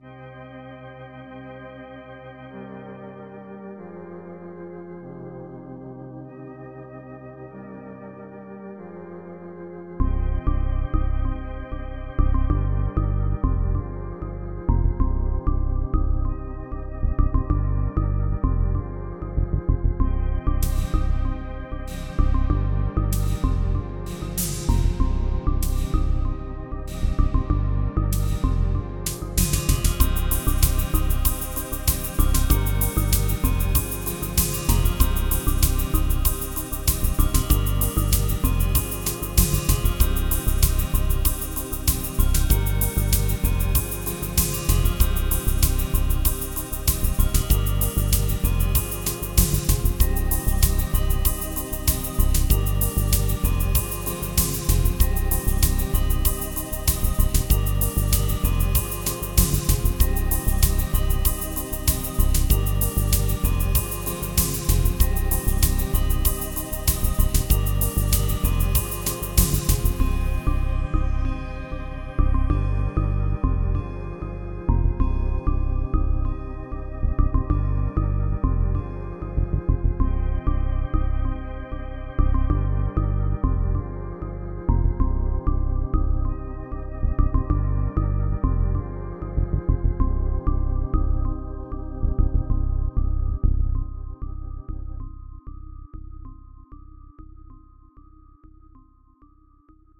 • Music has an ending (Doesn't loop)